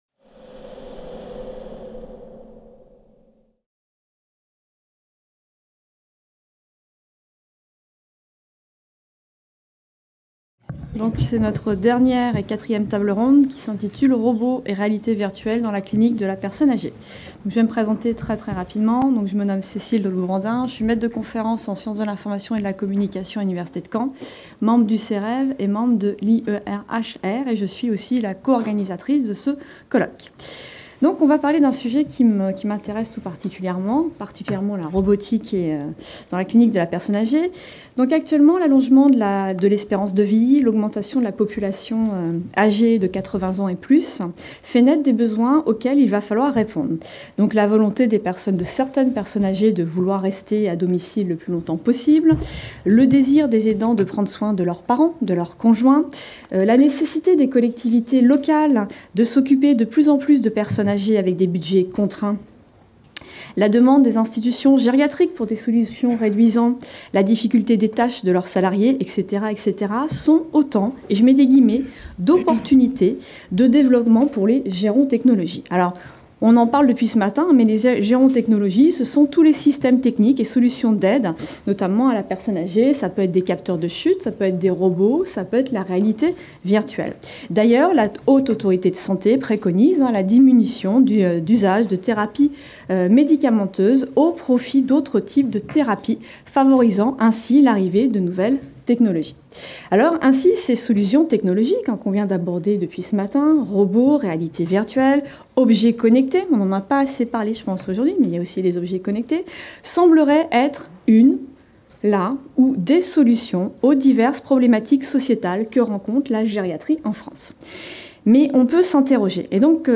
4e Table ronde : Robots et RV dans la clinique de la personne âgée